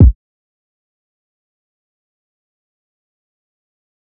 808_Boi 1 Da Kik.wav